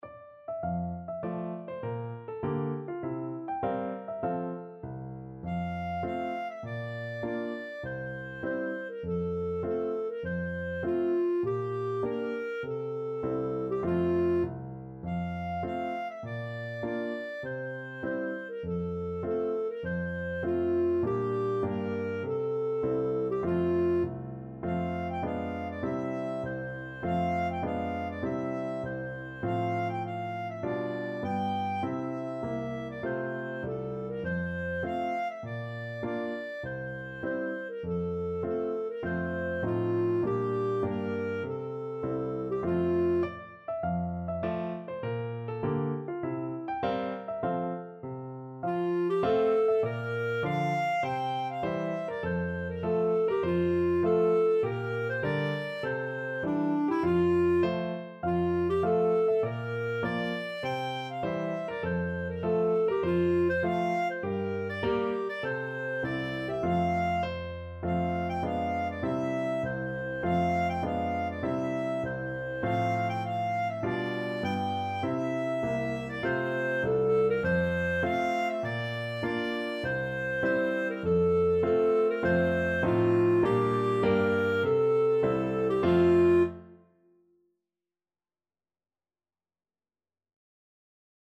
F major (Sounding Pitch) G major (Clarinet in Bb) (View more F major Music for Clarinet )
Moderato
4/4 (View more 4/4 Music)
Clarinet  (View more Easy Clarinet Music)
Traditional (View more Traditional Clarinet Music)